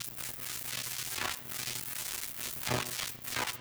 SFX_Static_Electricity_Short_03.wav